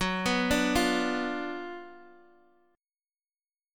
F#M7sus4#5 chord